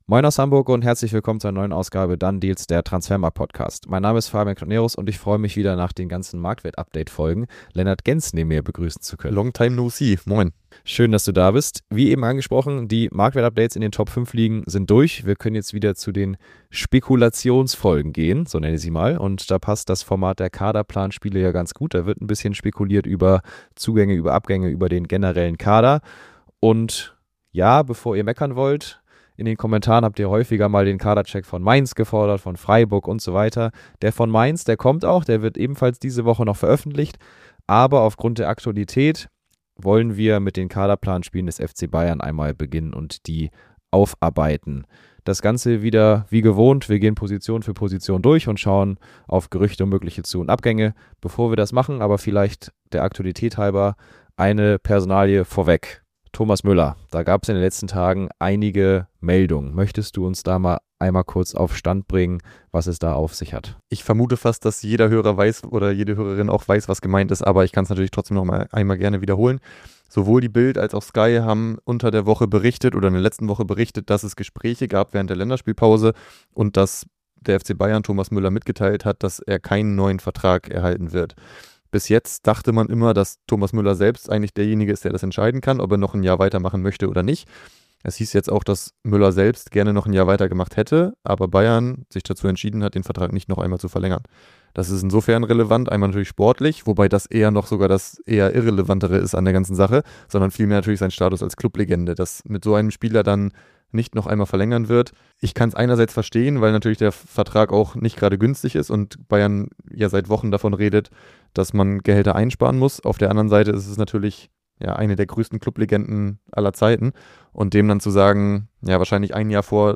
1 A substantive debate with an intelligent MAGA podcast host. Does he believe what he says? 50:35